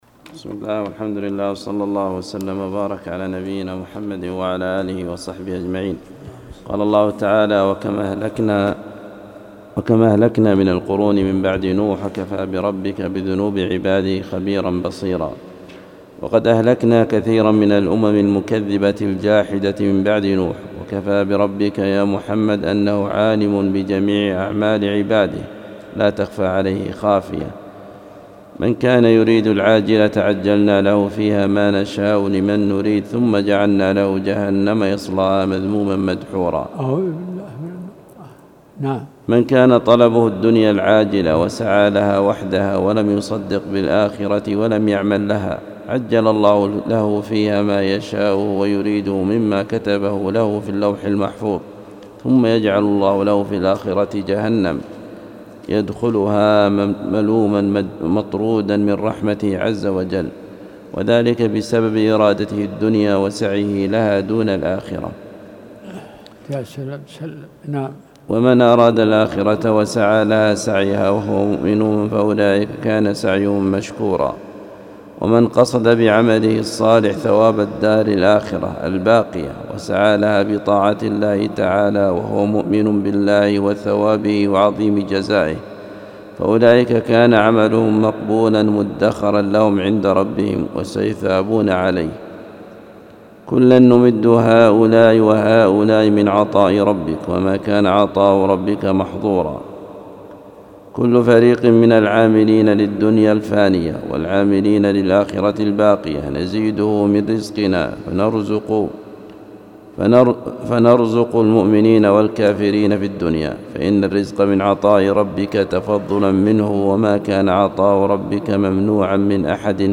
القرآن الكريم وعلومه     التفسير      سورة الإسراء